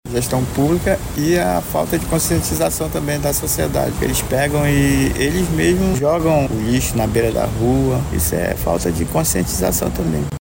Para o oficial, dois fatores contribuem para essa realidade.